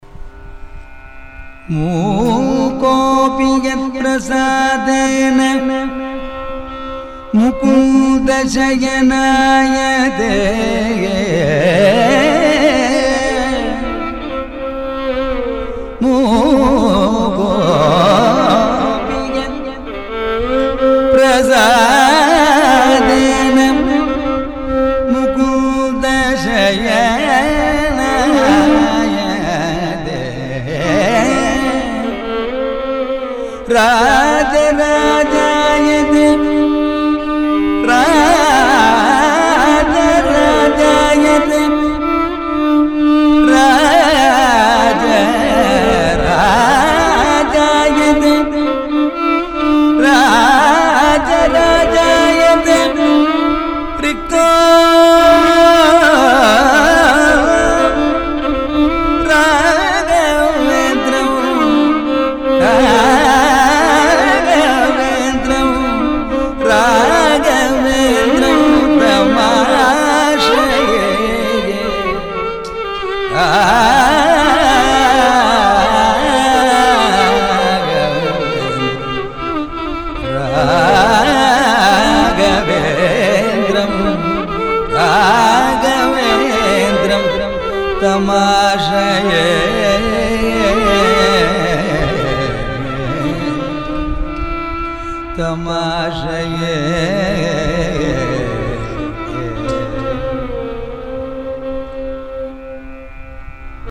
ಆದರೆ ಈ ಬಾರಿ ನನಗೆ ಕೆಲಸದ ಅತೀವ ಒತ್ತಡವಿದ್ದುದರಿಂದ ಇಬ್ಬರು ನಡೆಸಿದ ಸಂಗೀತೆ ಸೇವೆಯಲ್ಲಿ ನೇರವಾಗಿ ಭಾಗವಹಿಸುವ ಅವಕಾಶ ಸಿಗಲಿಲ್ಲ. ಆದರೆ ನನ್ನ ಪುಟ್ಟ ಆಫೀಸಿನಲ್ಲಿಯೇ ಕುಳಿತು ಈ ಸಂಗೀತ ಕಚೇರಿಯನ್ನು ಕಂಪ್ಯೂಟರಿನಲ್ಲಿ ರೆಕಾರ್ಡ್ ಮಾಡಿಕೊಂಡಿದ್ದೇನೆ.
ಆಶ್ಚರ್ಯವೆಂಬಂತೆ ಅದು ಮೋಹನಕಲ್ಯಾಣಿರಾಗದಲ್ಲಿಯೇ ಮೂಡಿ ಬಂದಿದೆ.